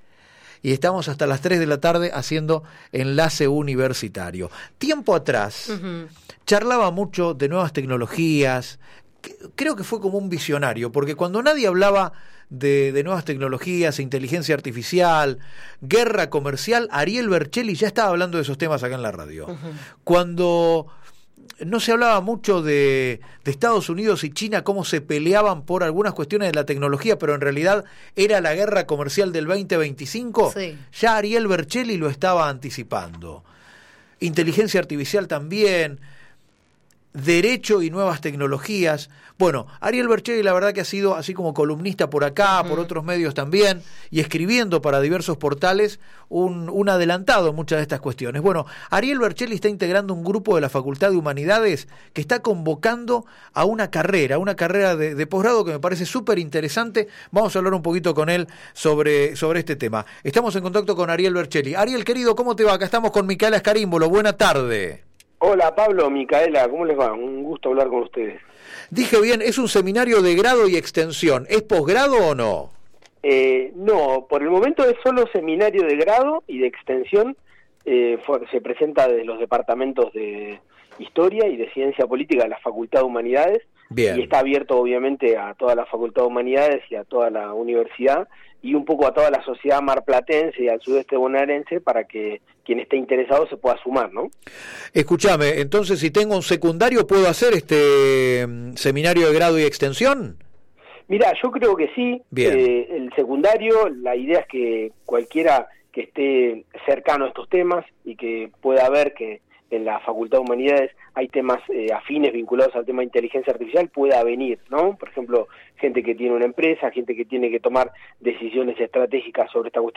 Entrevista en Radio UNMdP, Enlace Universitario